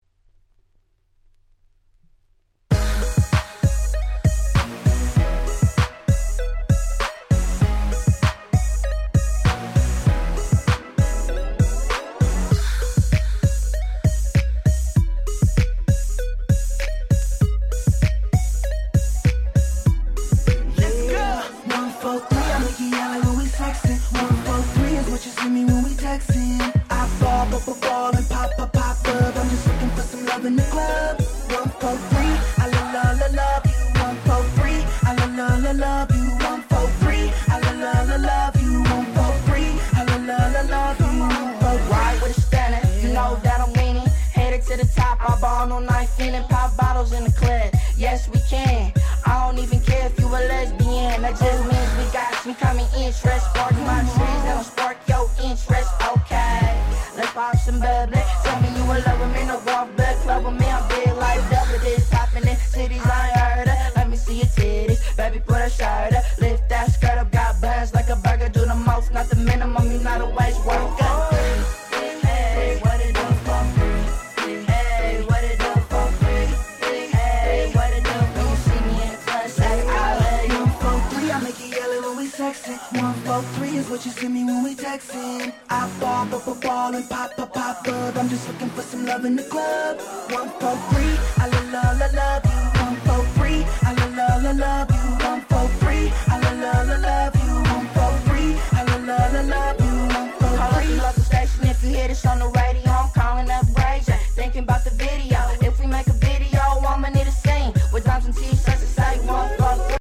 10' Big Hit Hip Hop !!